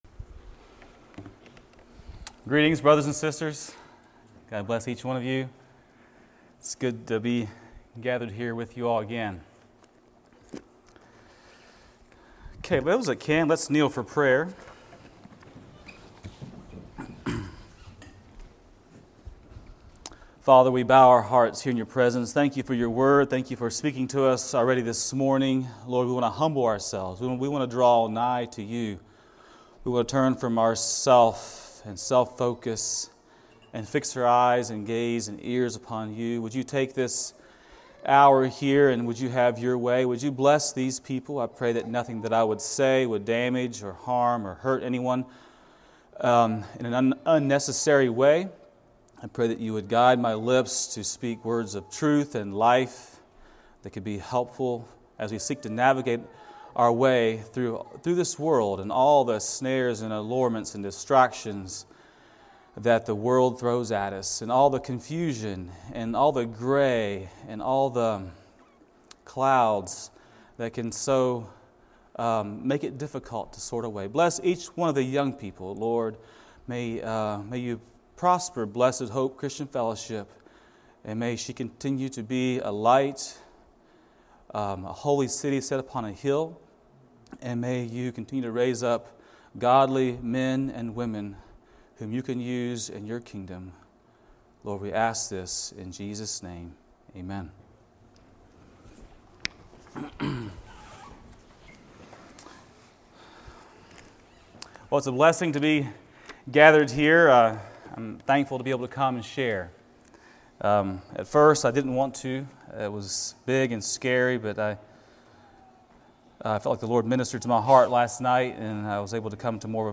Sermons of 2020 - Blessed Hope Christian Fellowship